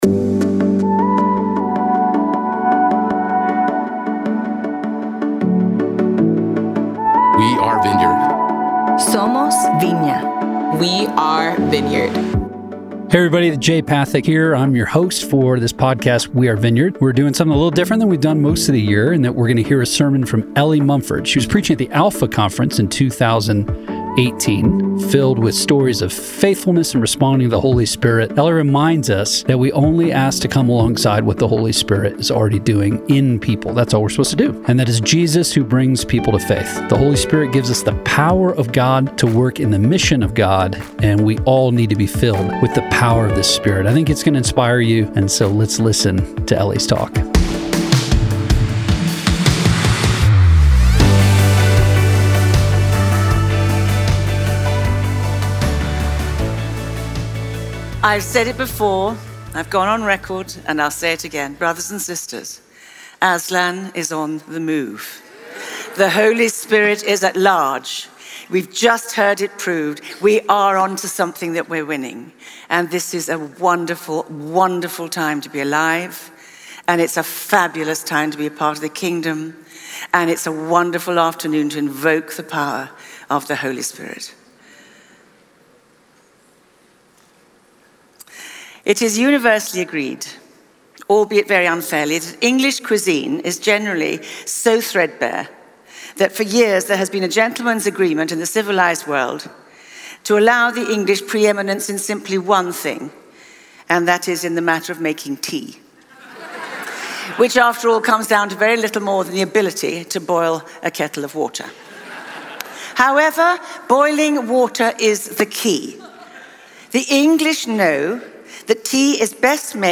Live from Alpha Conference